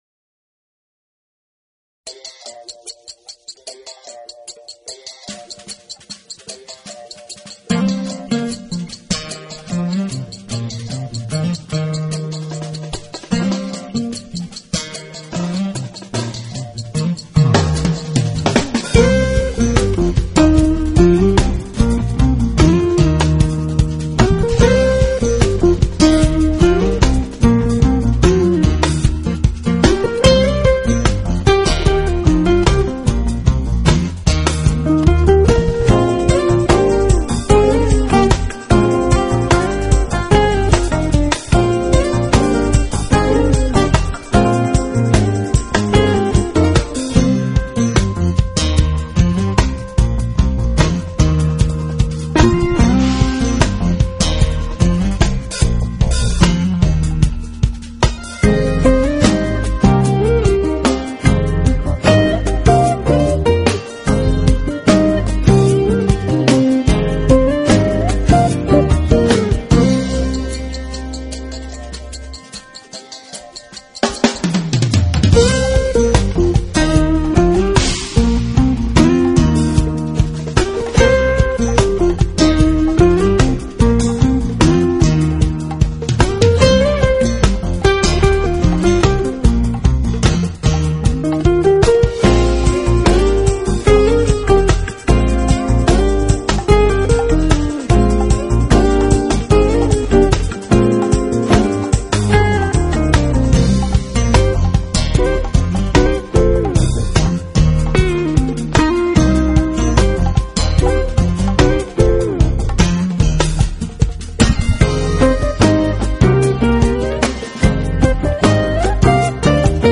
【爵士吉他】
音乐类型：Jazz